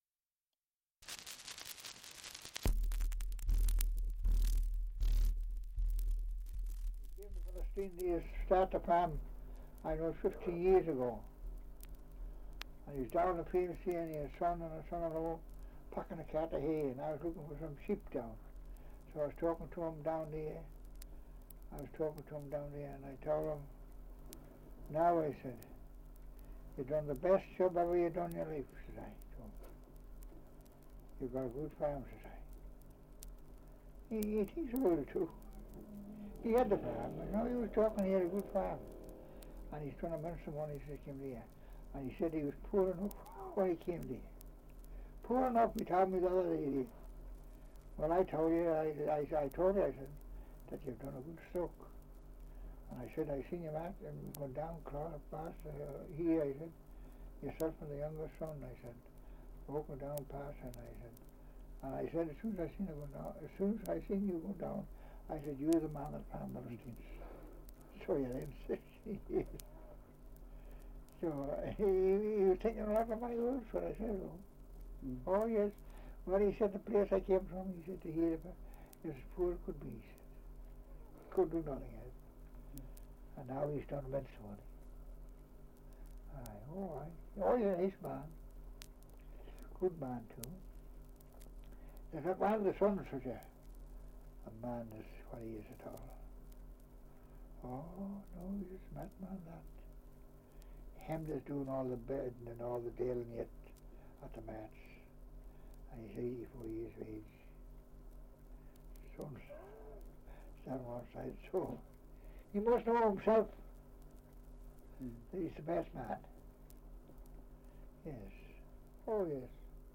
Survey of English Dialects recording in Andreas, Isle of Man
This is an edited version of an interview which is available in its complete form at C908/10 C5 (British Library reference number).
78 r.p.m., cellulose nitrate on aluminium